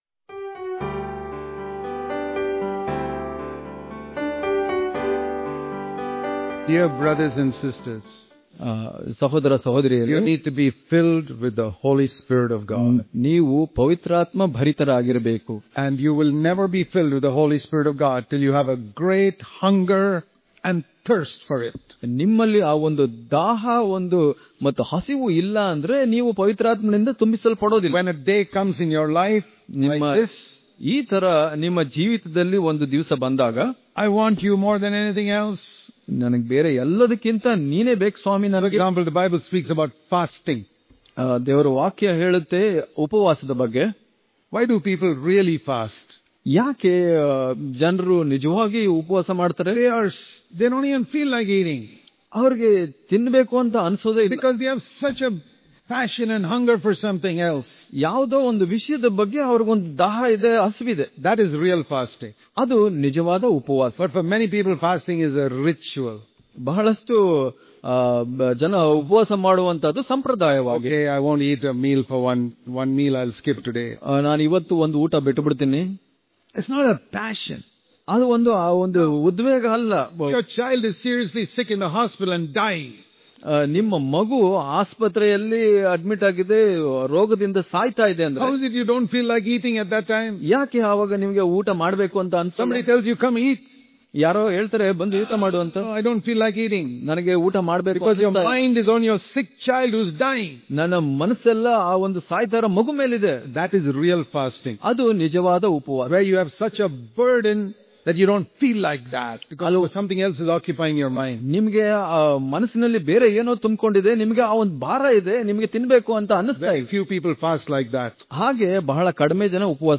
December 7 | Kannada Daily Devotion | Hunger And Thirst For The Holy Spirit Daily Devotions